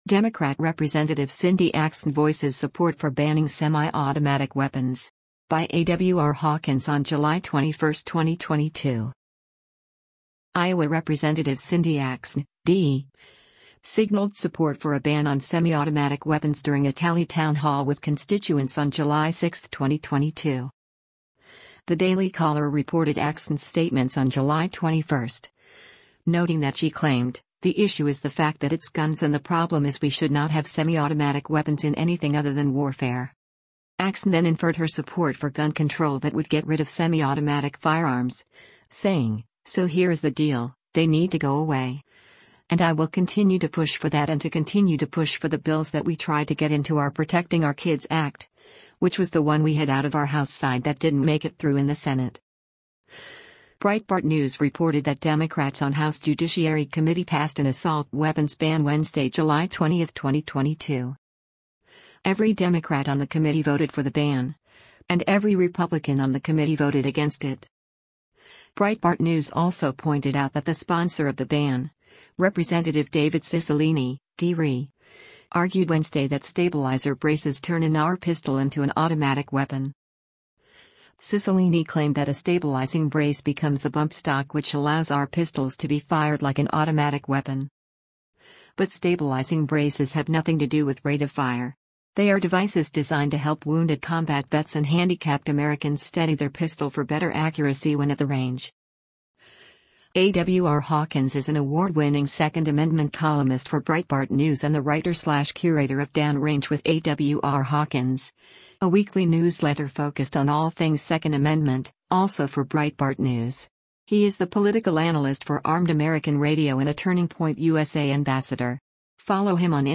Iowa Rep. Cindy Axne (D) signaled support for a ban on “semi-automatic” weapons during a tele-town hall with constituents on July 6, 2022.